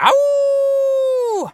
wolf_howl_02.wav